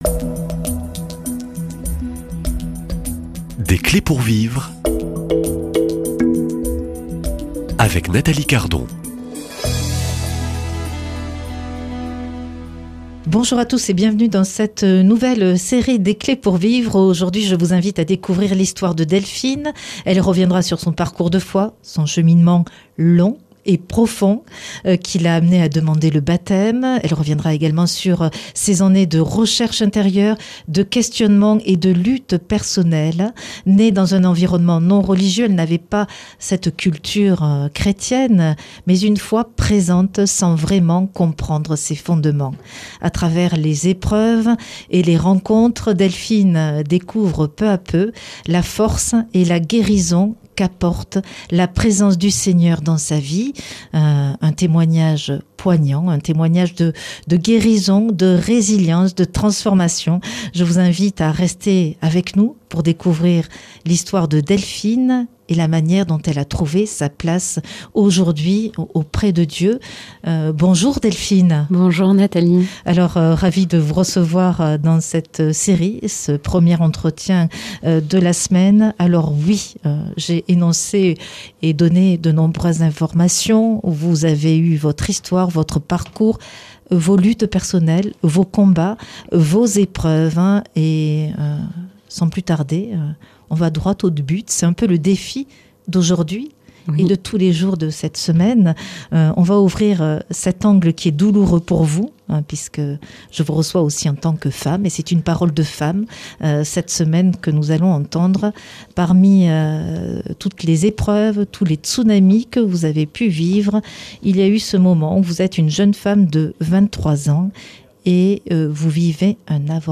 Un témoignage poignant de transformation, de guérison et de résilience.